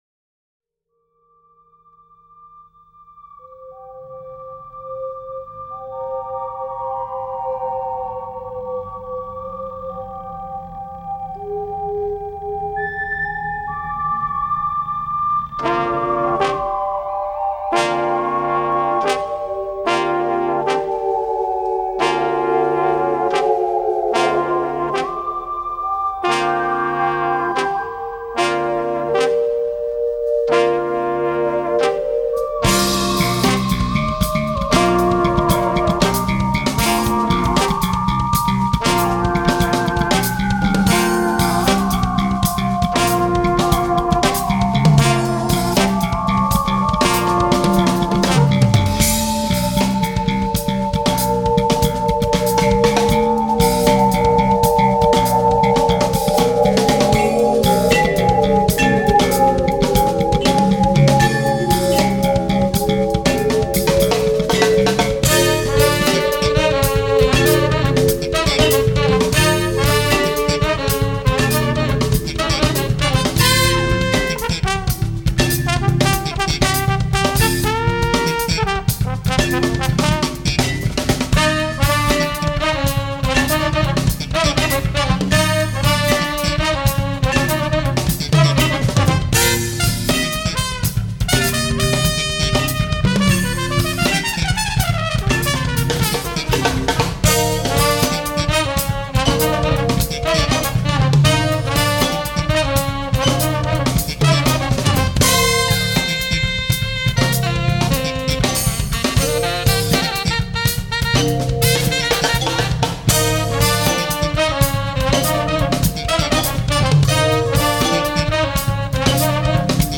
drums / percussions
bass
woodwinds
trombone
trumpet / flughorn
midi vibes